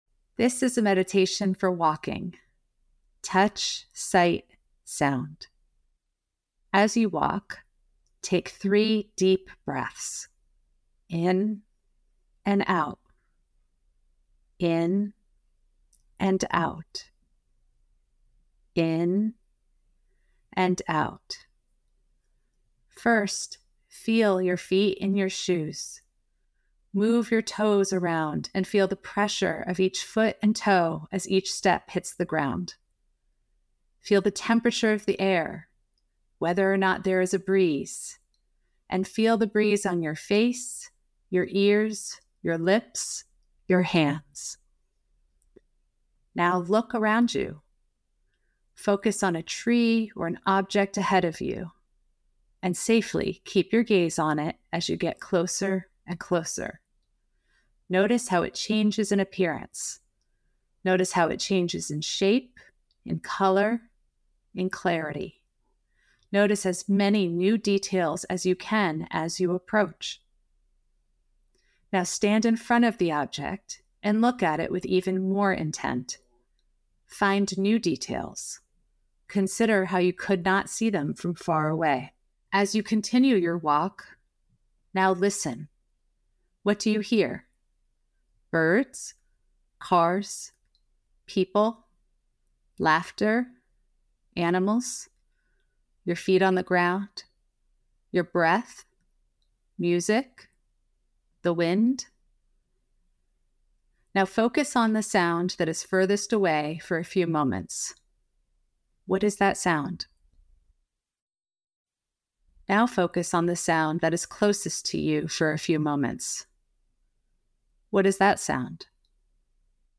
Hate the idea of meditation? this one is for you! this is a 2 minute active visualization perfect for listening while you’re walking. a win win!